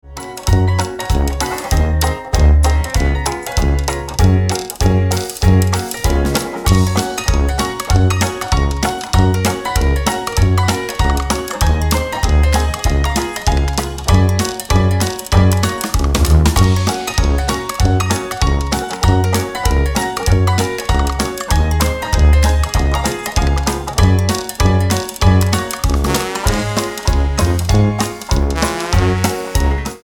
--> MP3 Demo abspielen...
Tonart:F#-G ohne Chor